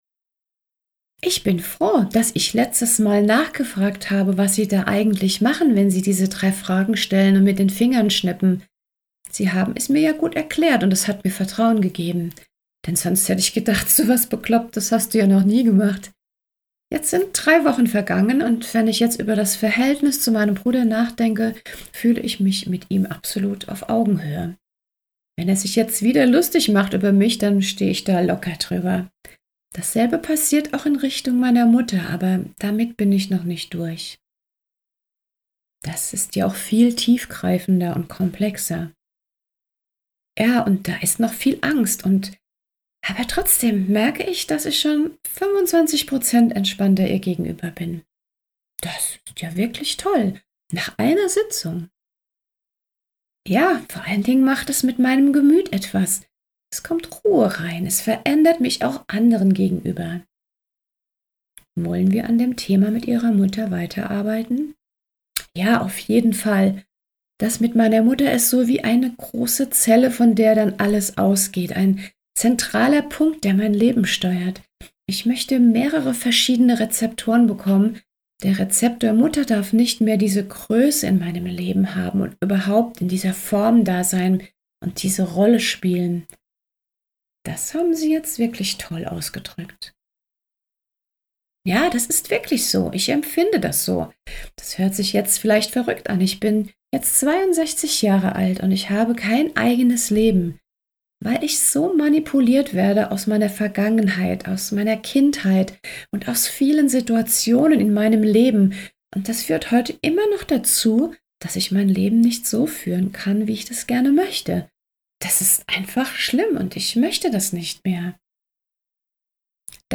Willkommen in der Hörbuch Welt!
Blockaden-und-Trauma-loesen-mit-Freemotion-Coaching-Hoerprobe.mp3